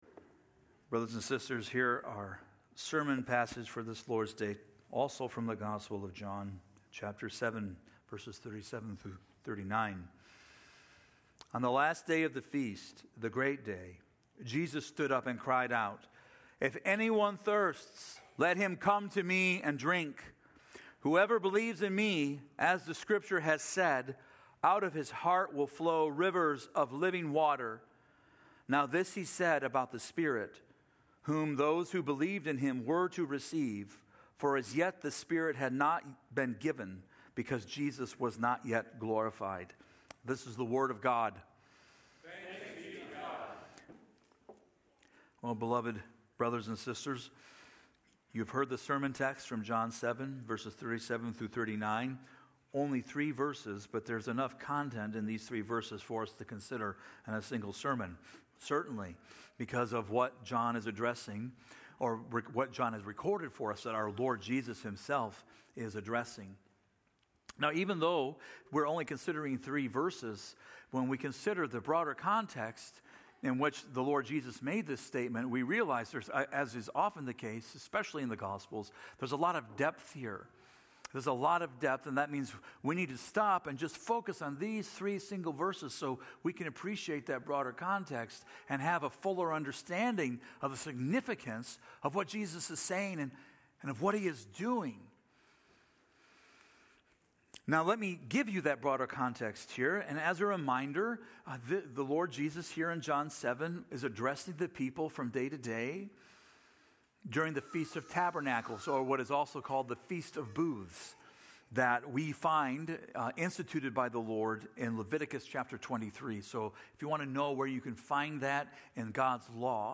TRC_Sermon-6.15.25.mp3